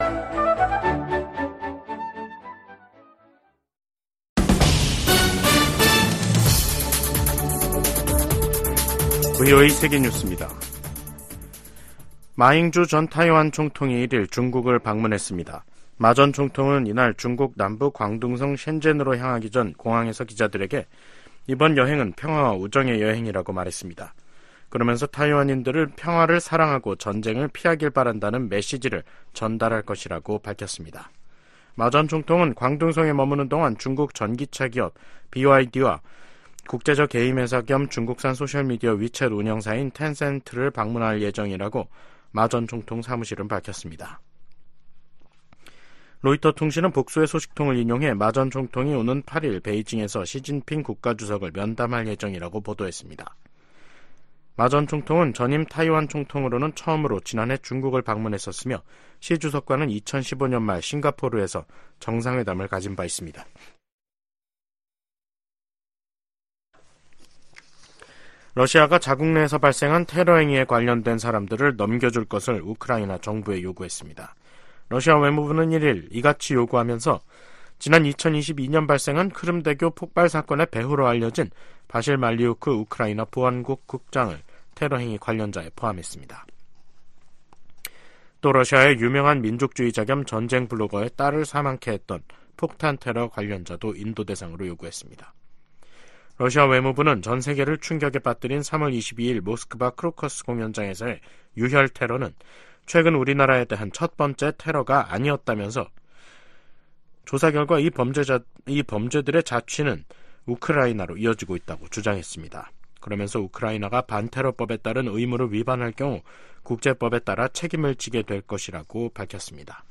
VOA 한국어 간판 뉴스 프로그램 '뉴스 투데이', 2024년 4월 1일 3부 방송입니다. 미국은 유엔 안보리 대북제재위 전문가패널의 활동 종료가 서방의 책임이라는 러시아 주장을 일축하고, 이는 북한의 불법 무기 프로그램을 막기 위한 것이라고 강조했습니다. 미국과 한국, 일본이 북한의 사이버 위협에 대응하기 위한 실무그룹 회의를 열고 지속적인 협력 방침을 확인했습니다.